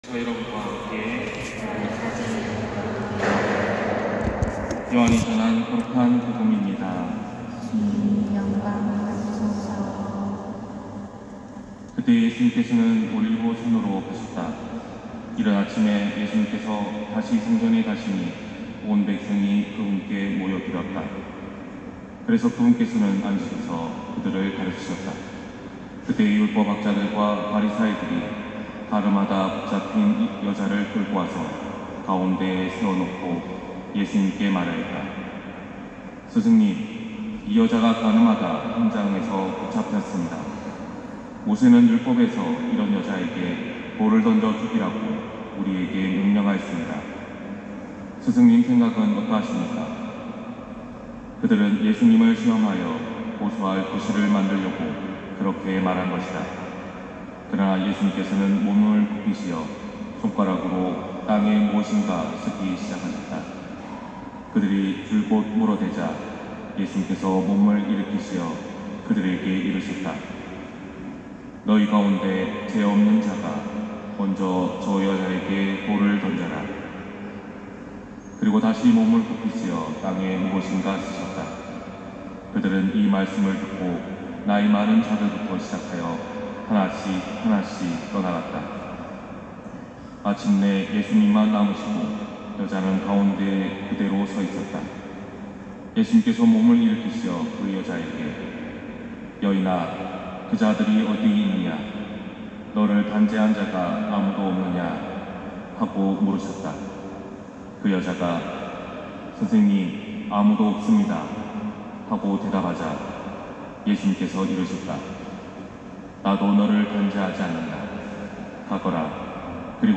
250405신부님 강론말씀